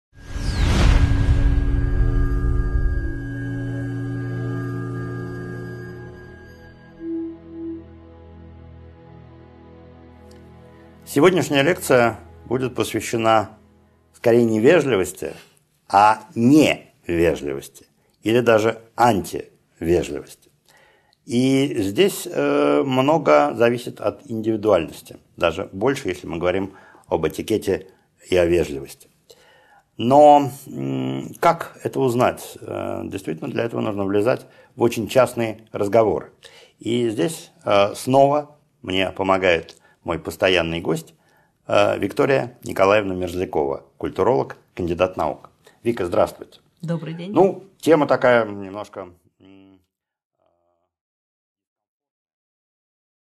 Аудиокнига 10.1 Диалог о не-вежливости и антивежливости | Библиотека аудиокниг